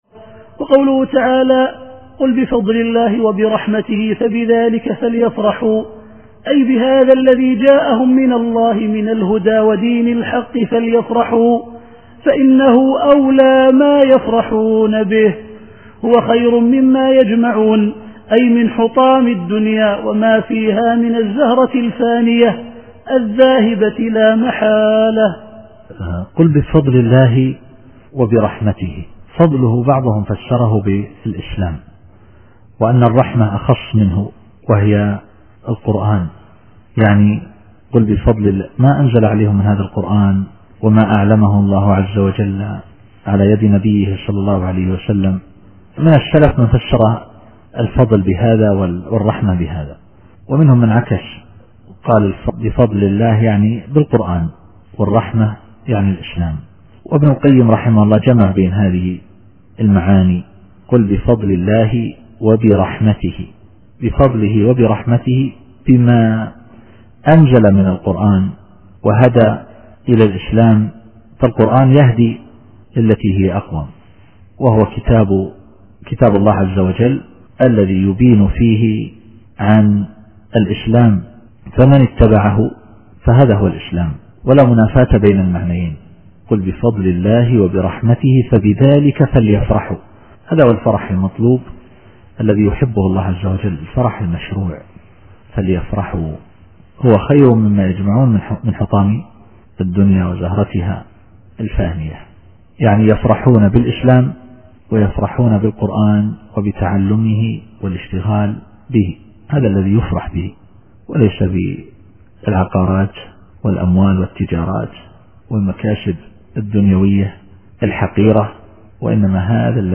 التفسير الصوتي [يونس / 58]